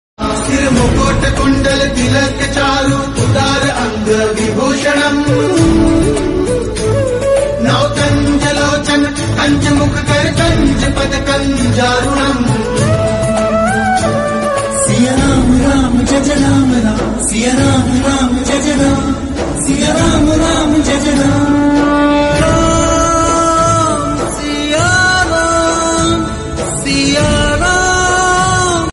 enjoy the divine devotional tune on your phone
easily and experience the melodious devotional music.
Set this serene and spiritual ringtone on your phone today.